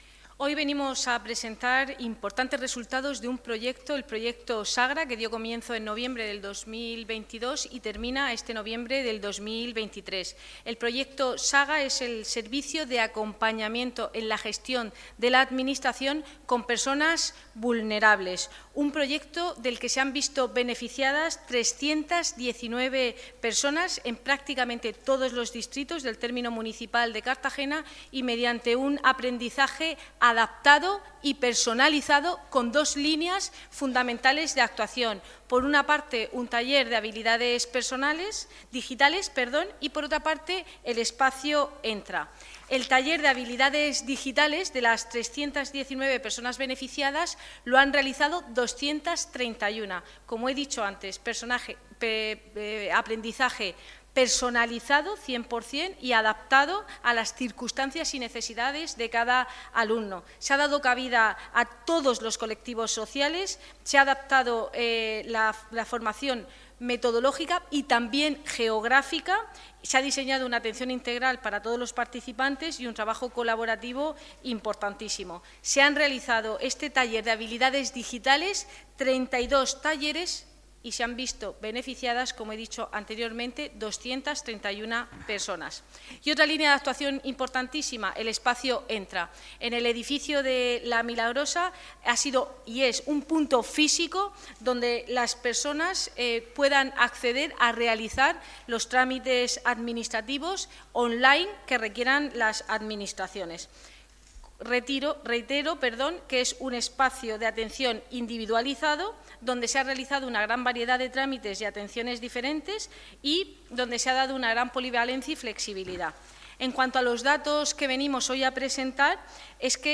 Enlace a Declaraciones de Cristina Mora sobre resultados del proyecto SAGA de Servicios Sociales